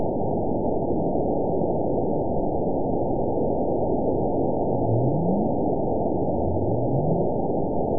event 911115 date 02/10/22 time 22:58:46 GMT (3 years, 4 months ago) score 9.67 location TSS-AB01 detected by nrw target species NRW annotations +NRW Spectrogram: Frequency (kHz) vs. Time (s) audio not available .wav